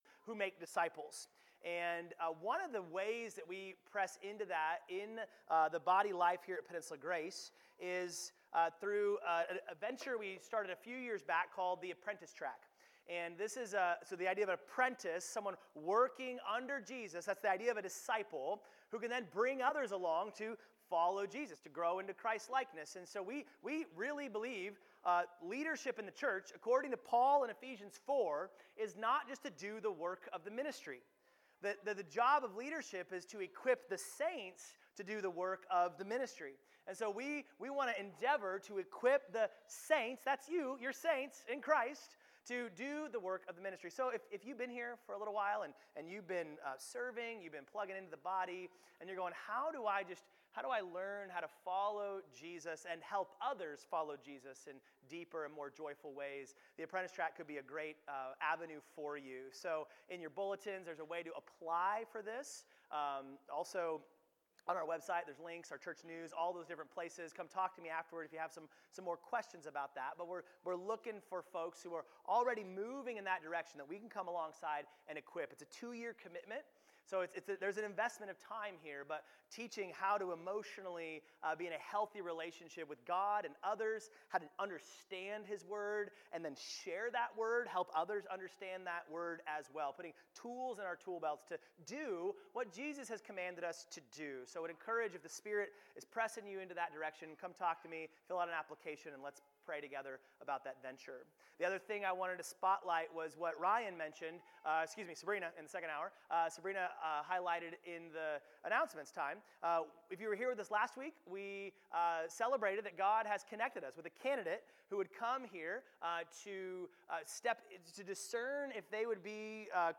Sermons | Peninsula Grace Church